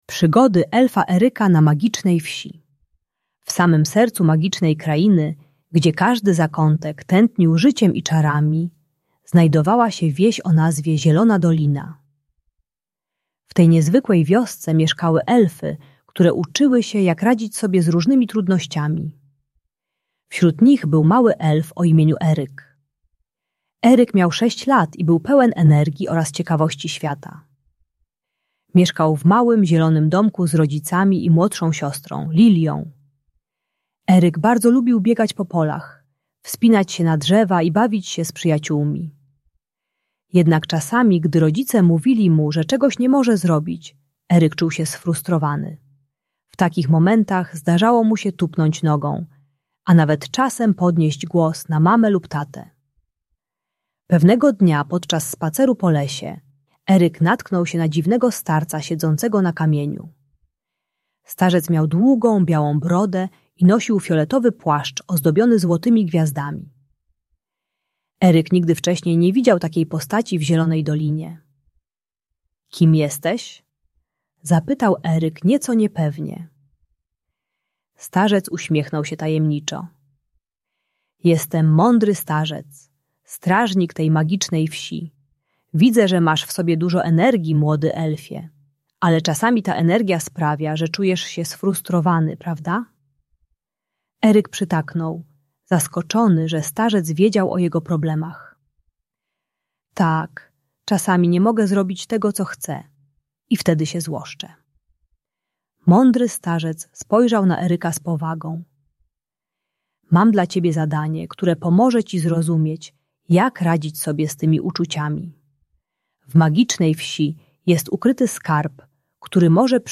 Przygody Elfa Eryka - Agresja do rodziców | Audiobajka